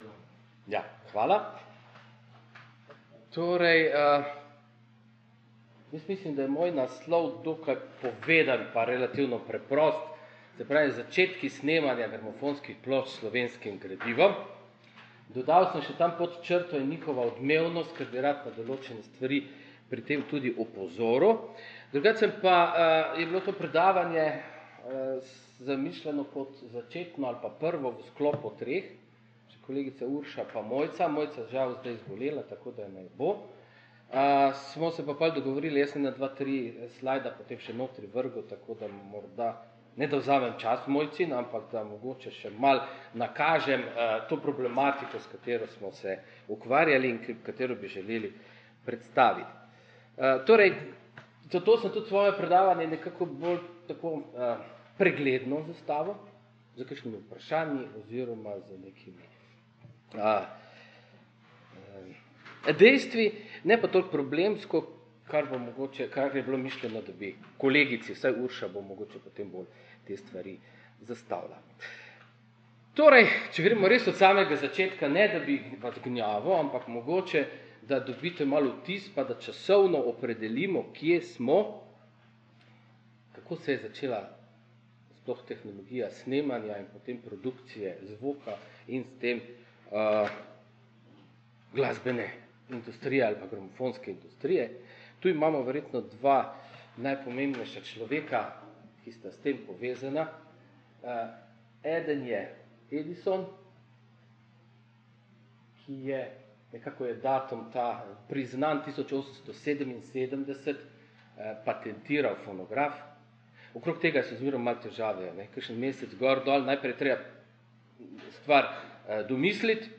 28. in 29. novembra 2014 se je na Oddelku za etnologijo in kulturno antropologijo Filozofske fakultete v Ljubljani odvijal znanstveni posvet Odmevnost jugoslovanske glasbe in sodobne popularne glasbe z območja nekdanje Jugoslavije.